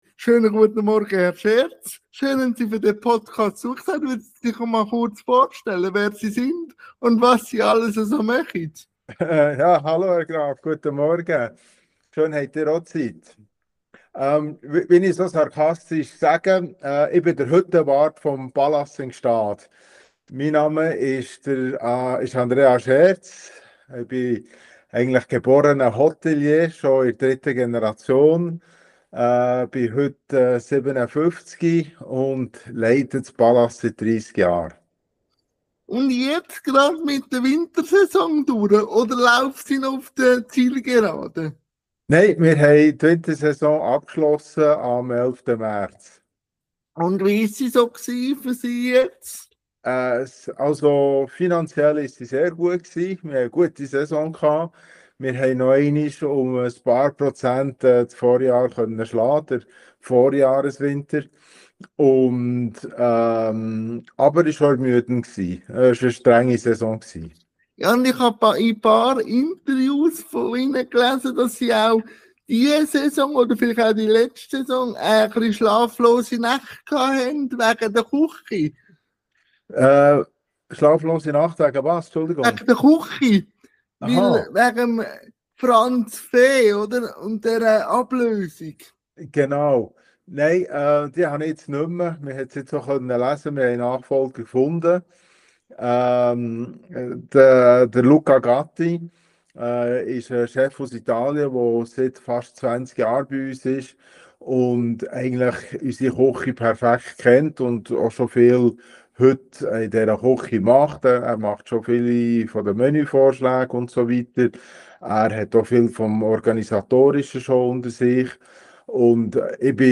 INTERVIEW-THEMEN | Berufung, Gstaad Palace u. v. m LINKS